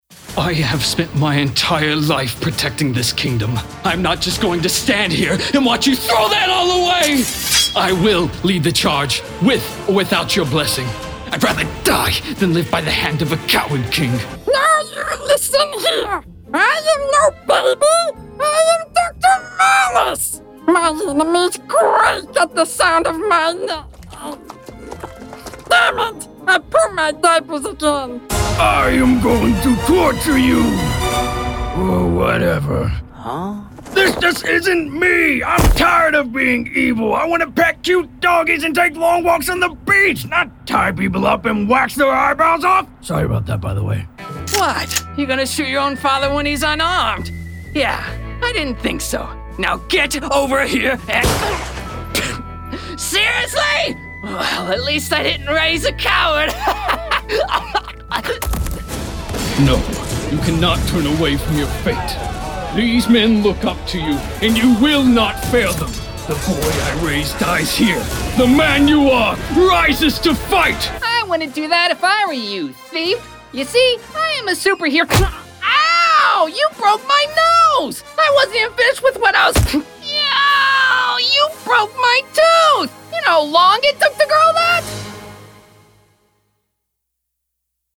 Character Demo